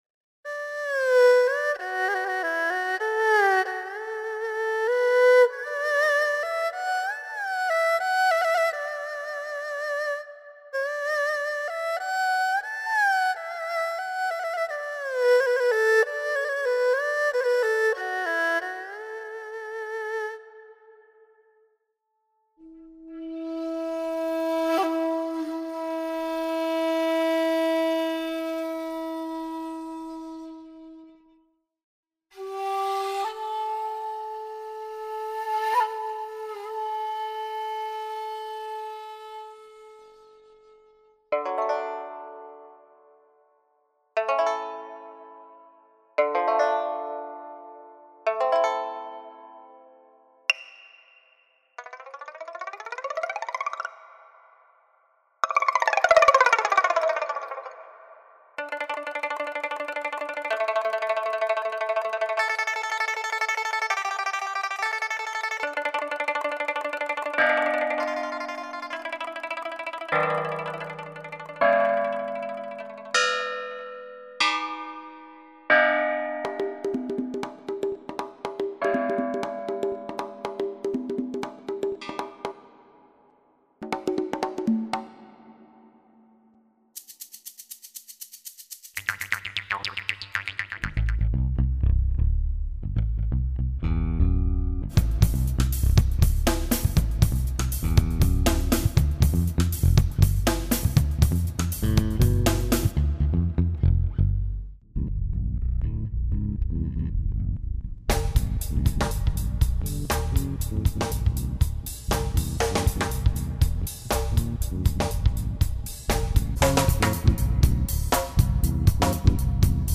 插件里的音色非常多，下面我随便弹了一下它里边的一些音色素材，包括打击Loop在内全部的音色都完全是这个插件里的。
偶认为这个音色盘的音质还是很不错的（混响为软件本身所带）